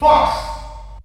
The announcer saying Fox's name in German releases of Super Smash Bros.
Fox_German_Announcer_SSB.wav